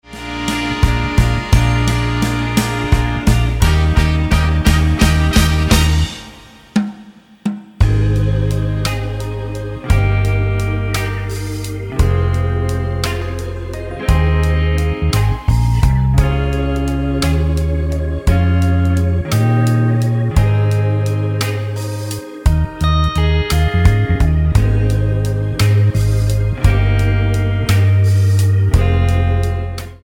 Tonart:Ab mit Chor